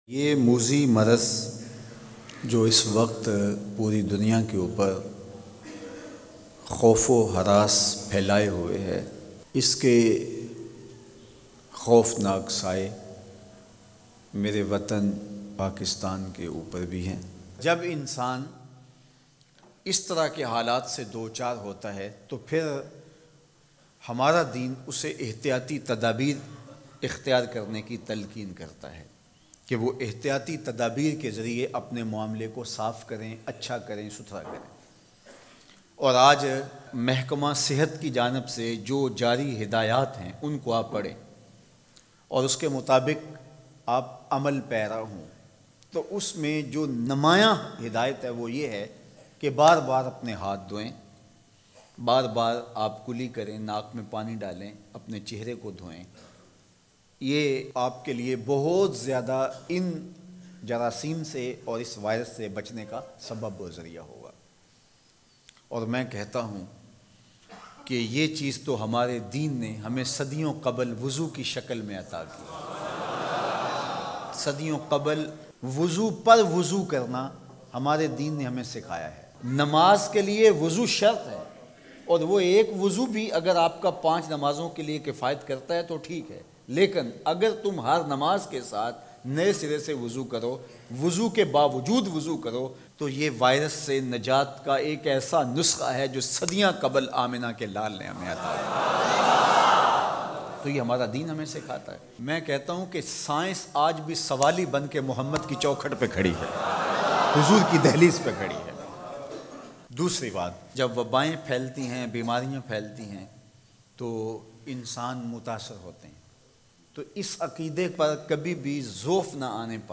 Corona se Bachaao Ki Ehtiyati Tadaabeer or Behtreen Wazaaif Bayan MP3